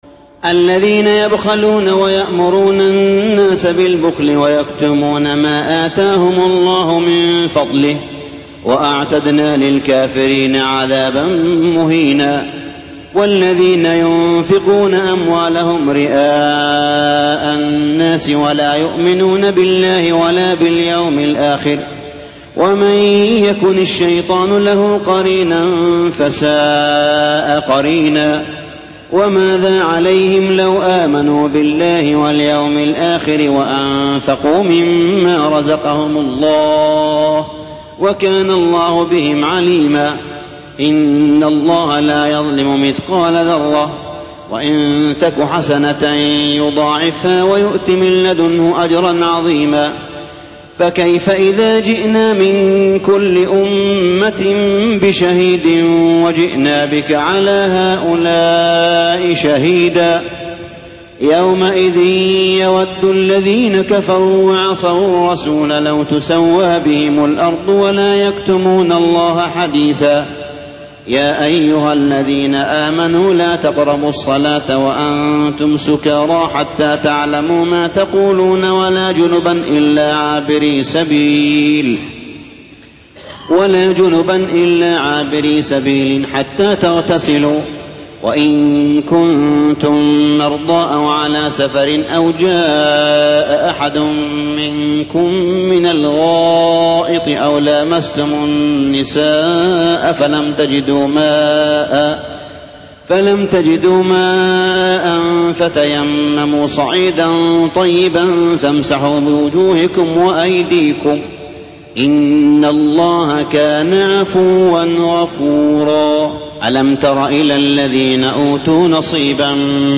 صلاة التراويح ليلة 6-9-1412هـ سورة النساء 37-96 | Tarawih prayer Surah An-Nisa > تراويح الحرم المكي عام 1412 🕋 > التراويح - تلاوات الحرمين